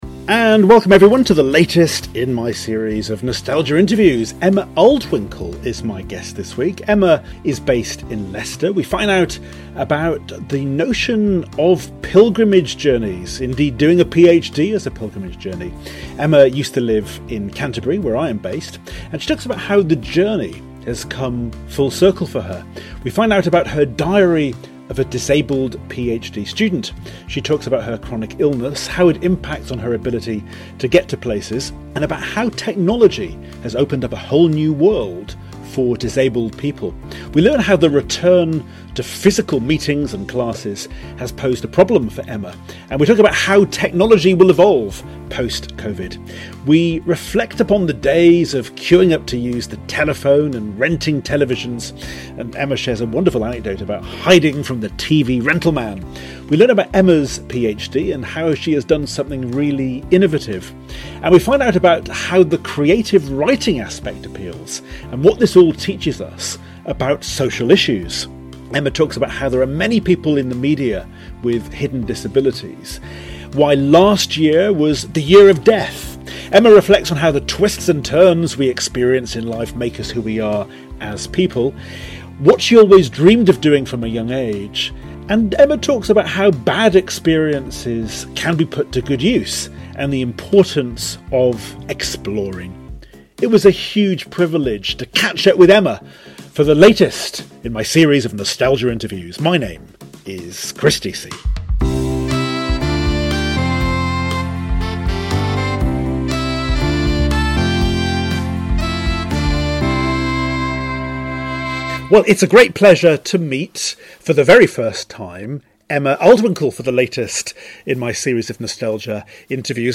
for my 200th Nostalgia Interview